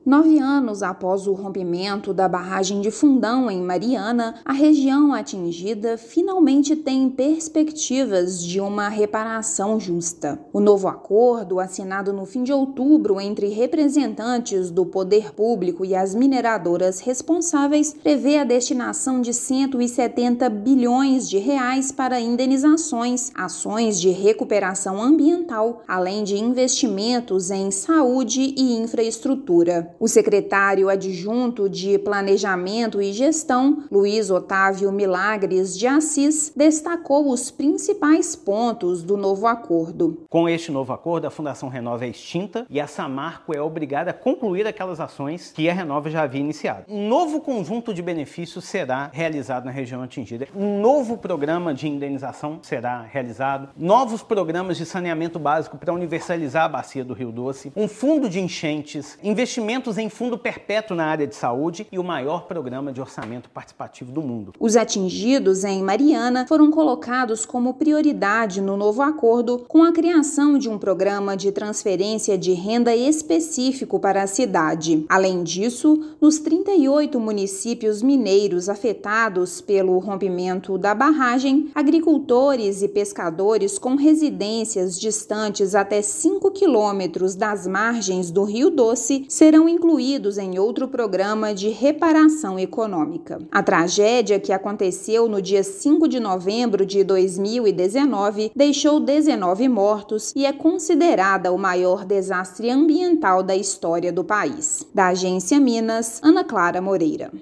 Governo de Minas apresentou desenho técnico do novo acordo a prefeitos dos municípios atingidos na última quinta-feira (31/10). Ouça matéria de rádio.